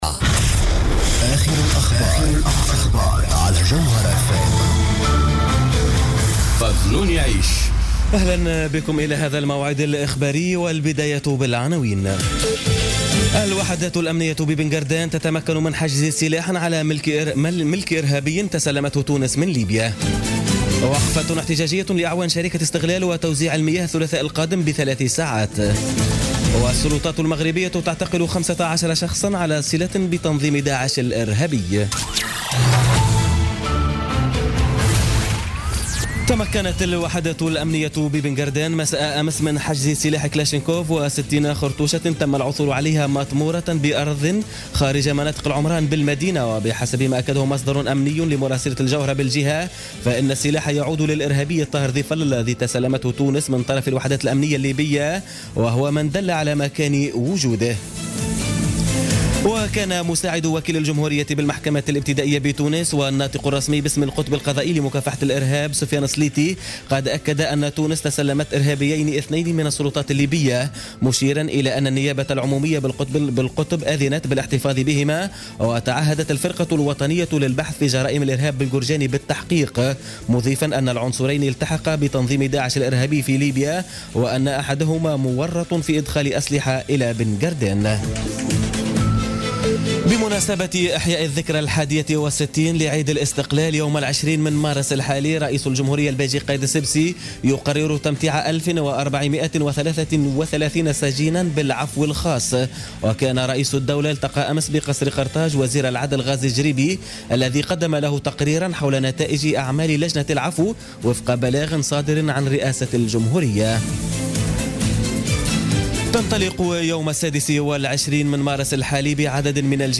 نشرة أخبار منتصف الليل ليوم السبت18 مارس 2017